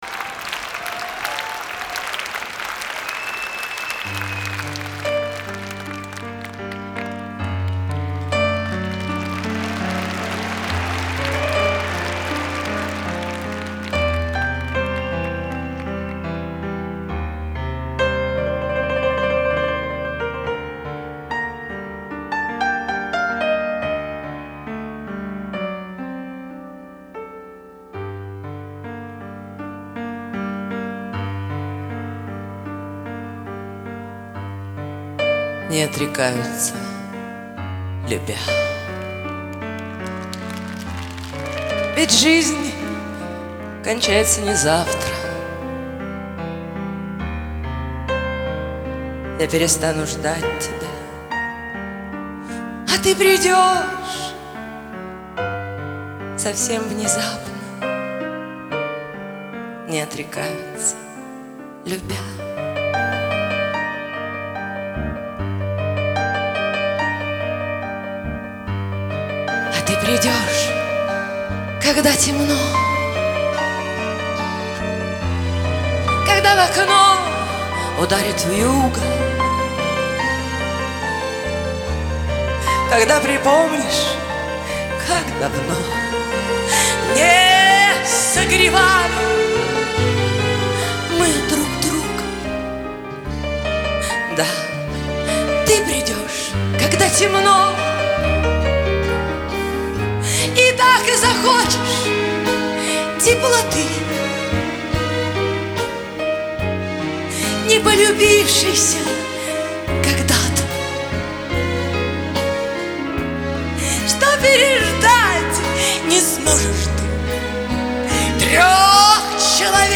По стыкам времен несется фортепьянный поток
советская эстрада